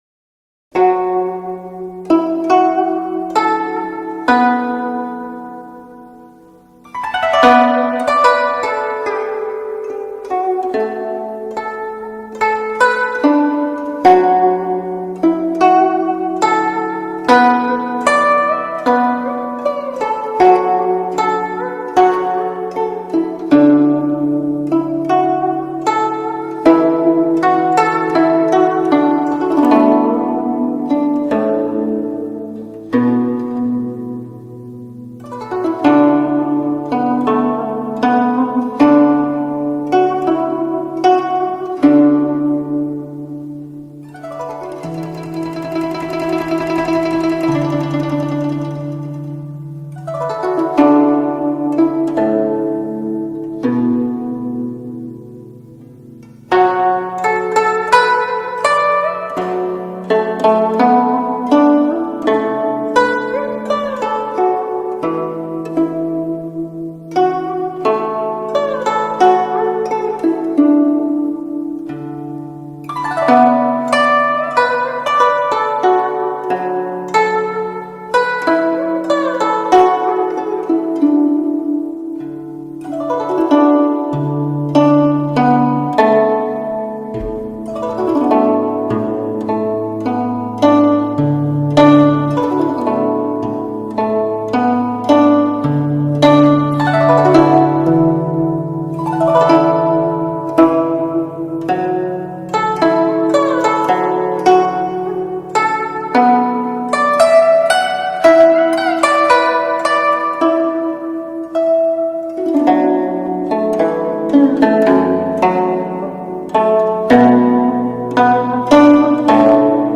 Música-Clásica-China-III.mp3